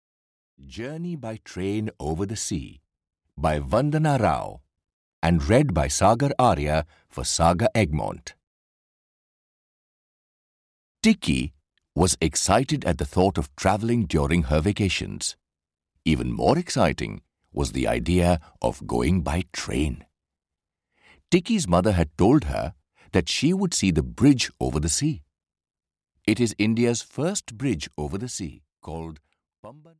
Audio knihaJourney by train over sea (EN)
Ukázka z knihy